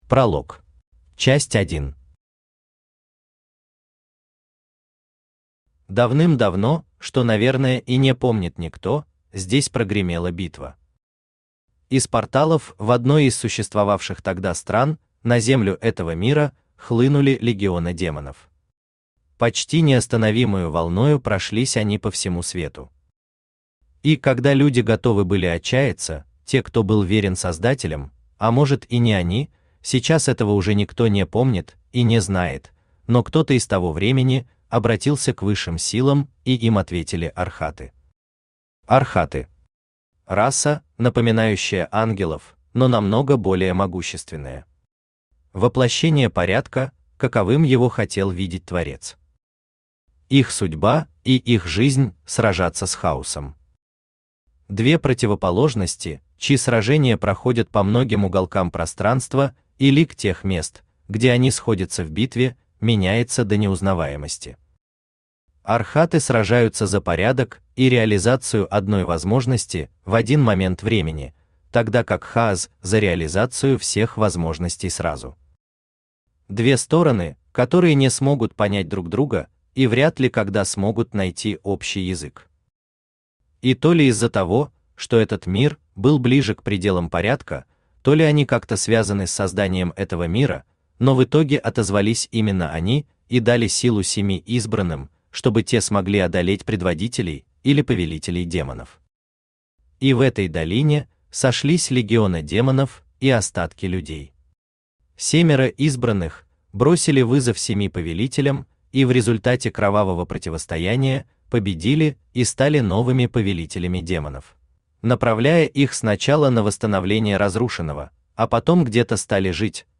Аудиокнига Книга 1.
Читает аудиокнигу Авточтец ЛитРес.